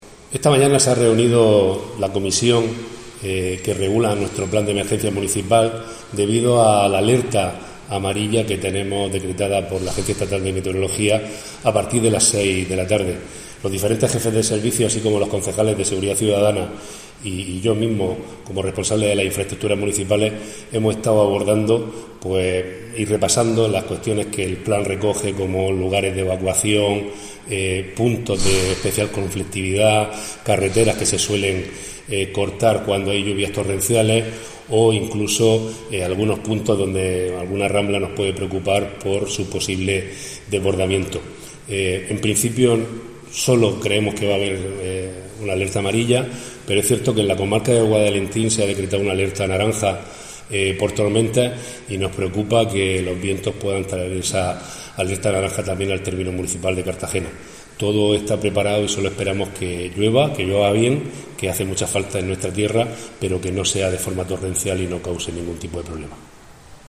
Enlace a Declaraciones de Diego Ortega sobre la reunión de coordinación de servicios municipales ante el episodio de lluvias.